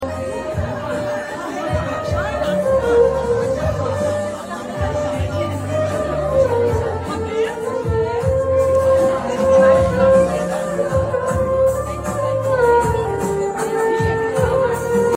افتتاحية موسم الصيف في كلينك لو رودان